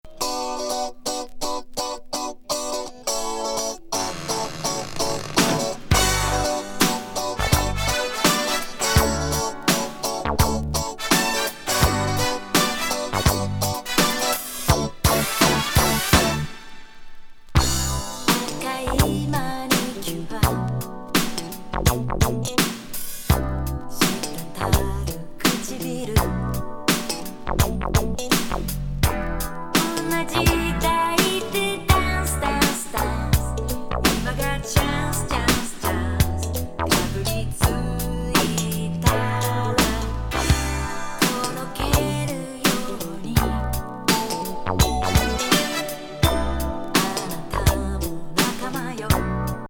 モダン・ソウル